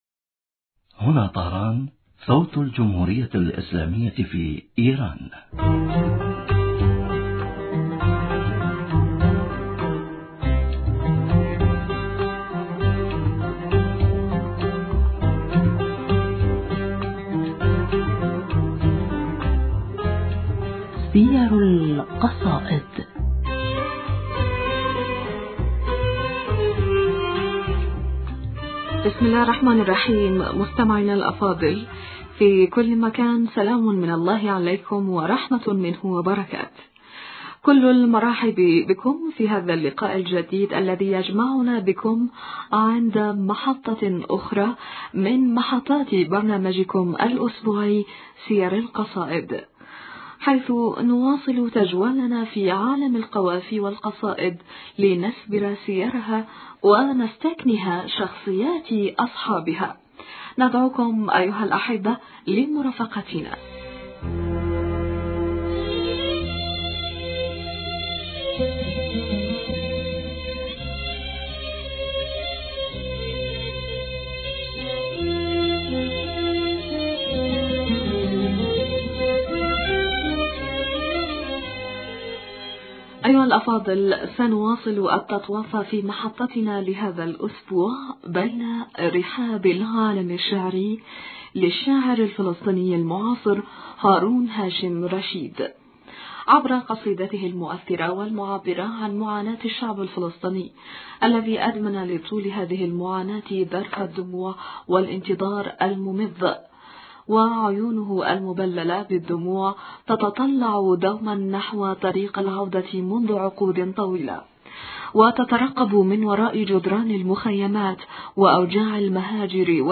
فلنستمع الى الأنغام الحزينة وهي تنطلق من النايات الناطقة بالغربة .